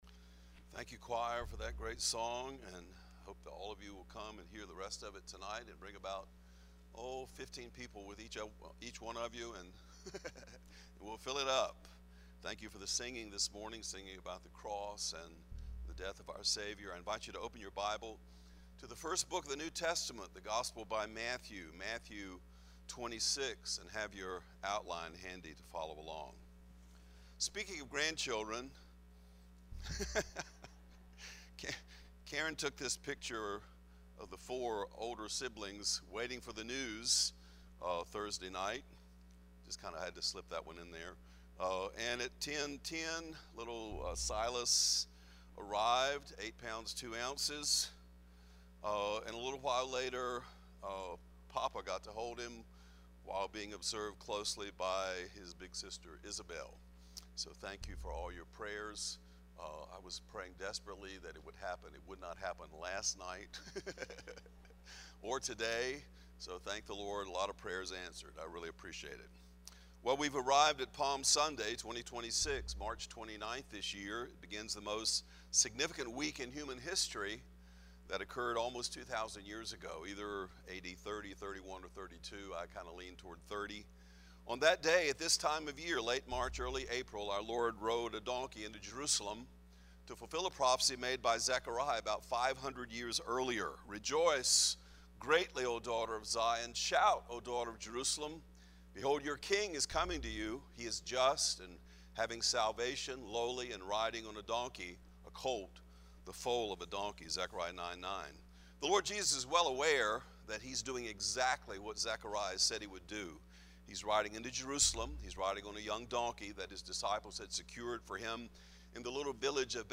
This is the third message